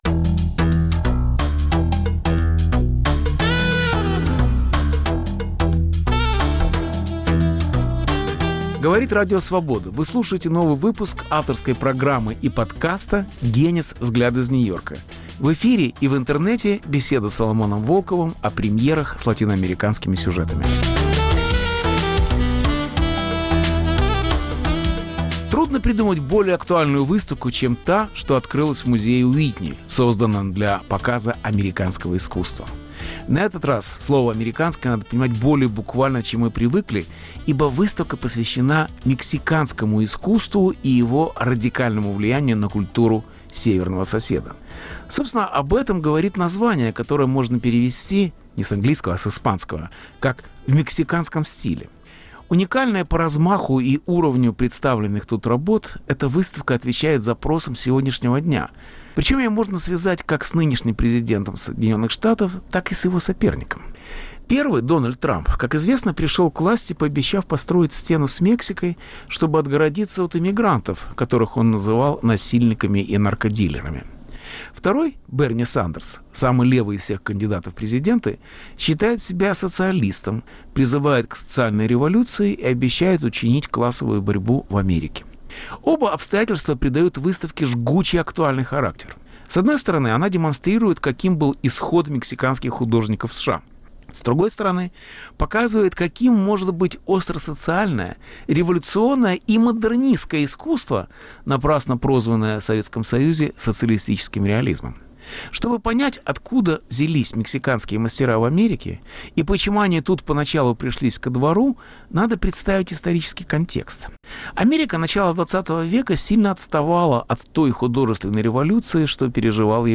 Повтор от 16 марта, 2020 Беседа с Соломоном Волковым о великих муралистах и их революционном искусстве 1)Мексиканские уроки самобытности в США 2) Храм труда в Детройте 3)Как Диего Ривера писал Ленина без Сталина